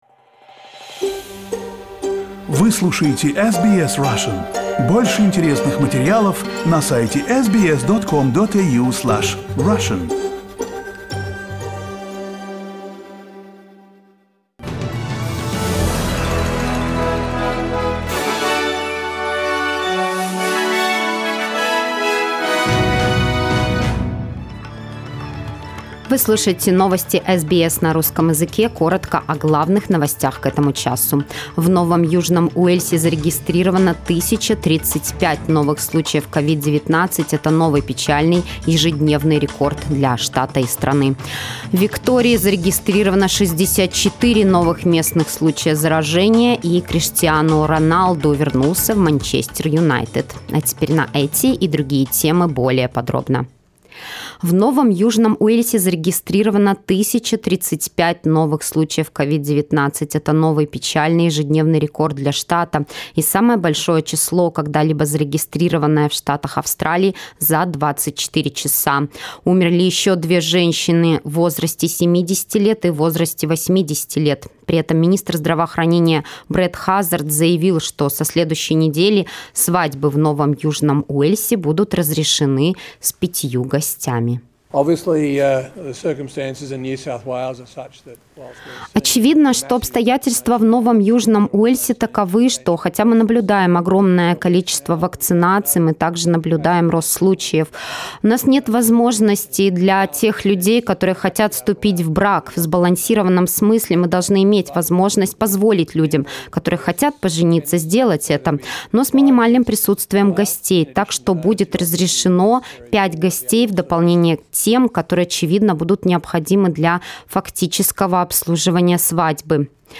SBS news in Russian - 28.08